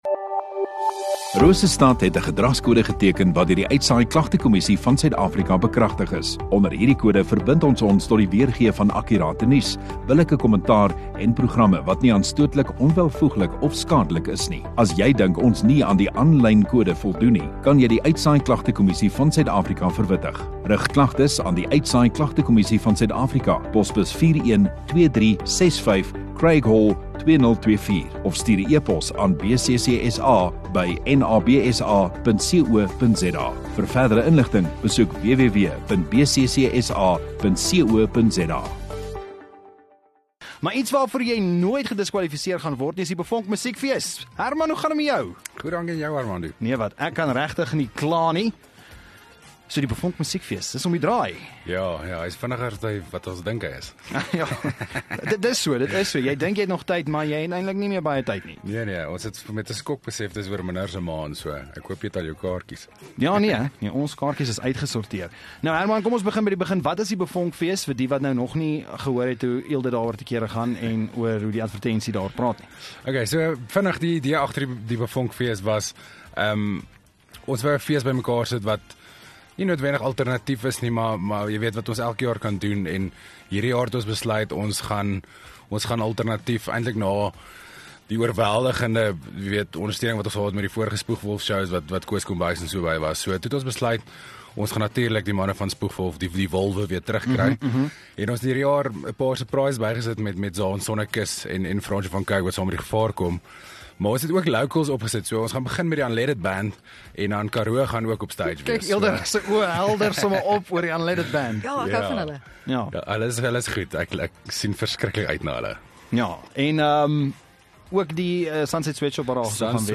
Radio Rosestad View Promo Continue Radio Rosestad Install Vermaak en Kunstenaars Onderhoude 18 Feb BeVonk musiekfees